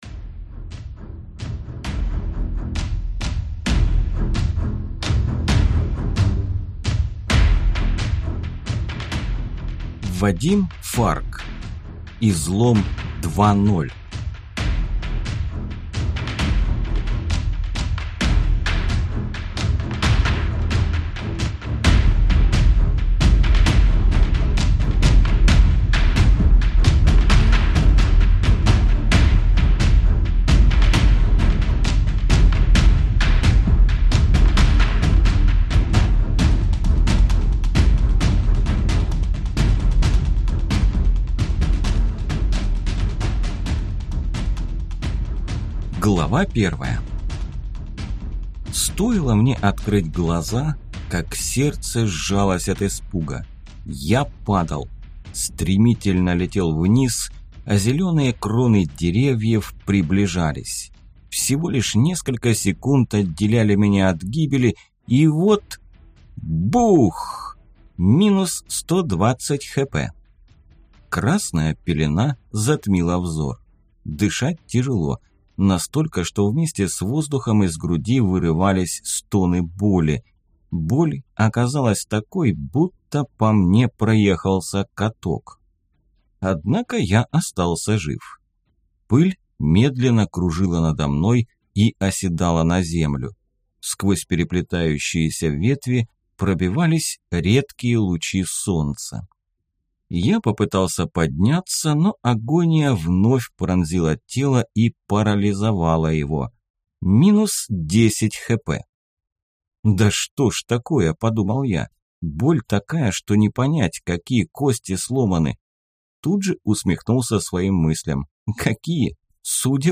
Аудиокнига Излом 2.0 | Библиотека аудиокниг